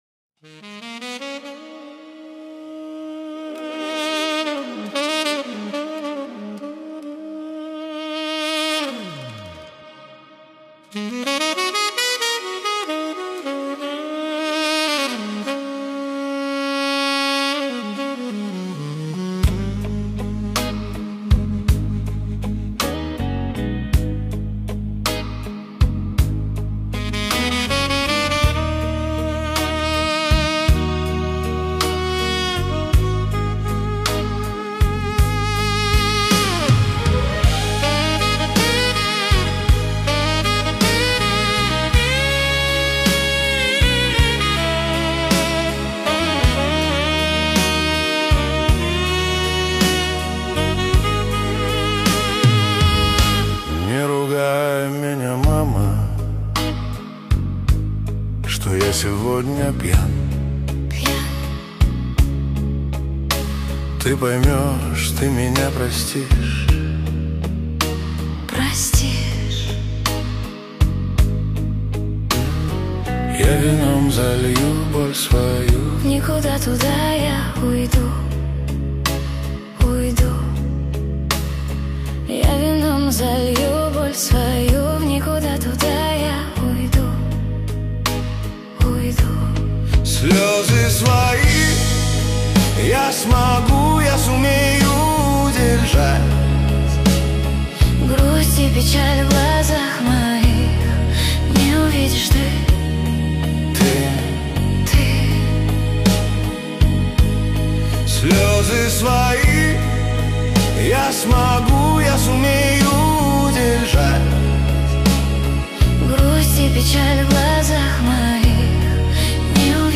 По мотивам песни